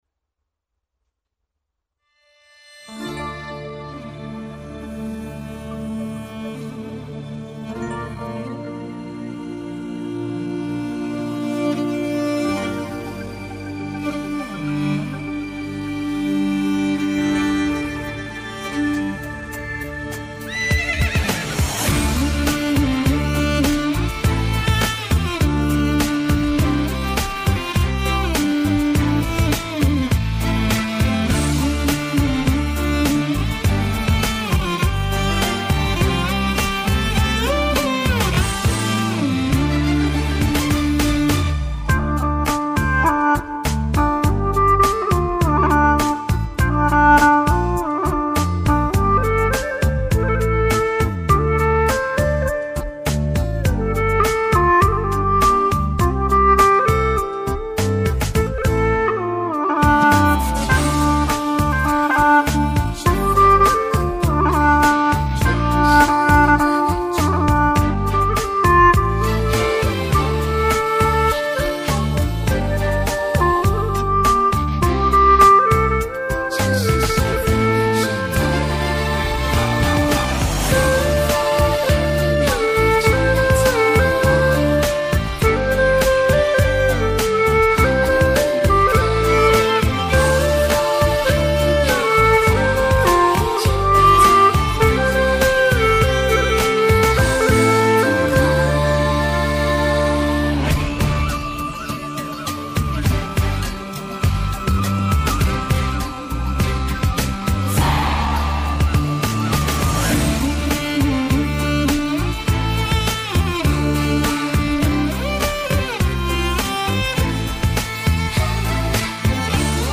调式 : F 曲类 : 流行